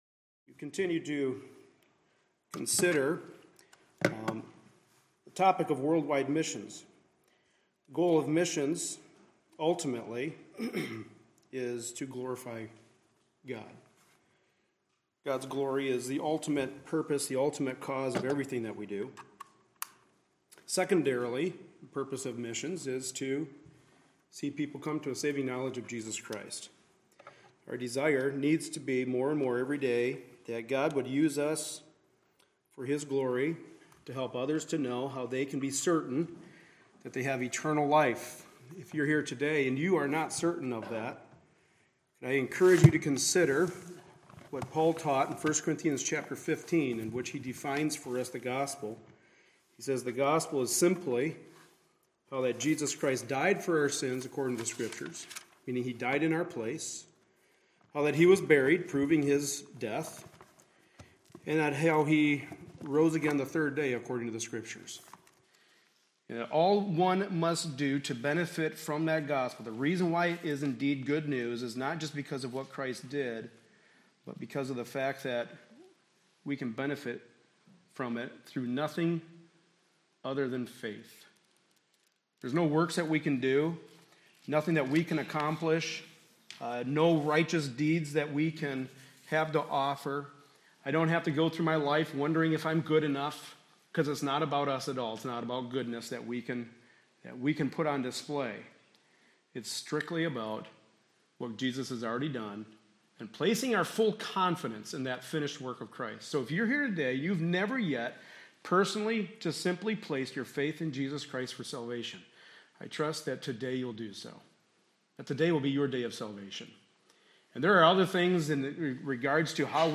Series: Missions and the Local Church Service Type: Sunday Morning Service